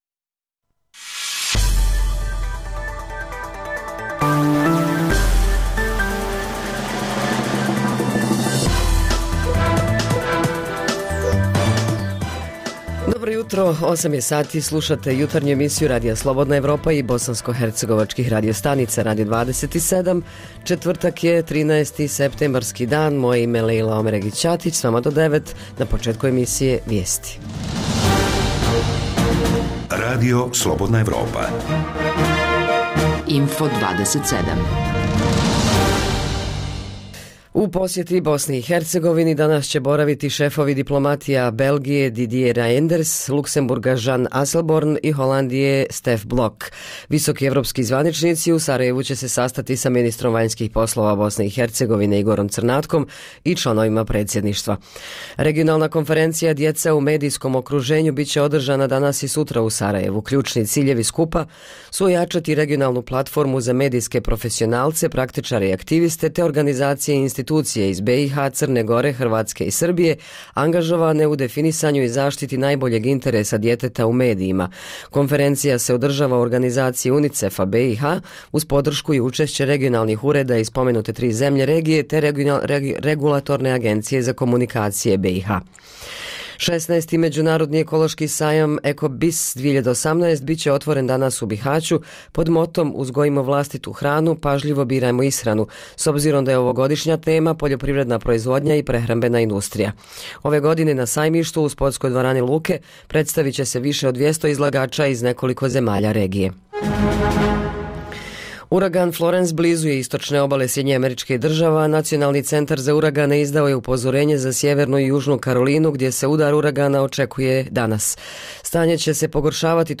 O tome priče bilježe naši dopisnici iz Doboja, Mostara i Banjaluke.